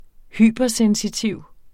Udtale [ ˈhyˀbʌˌ- ]